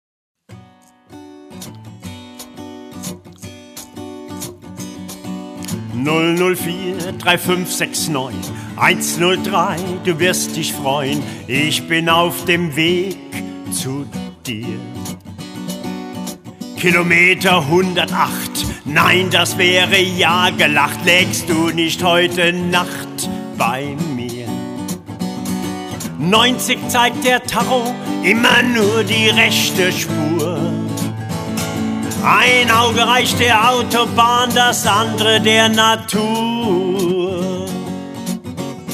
Authentische Lieder eines Abenteurers und Straßenmusikers.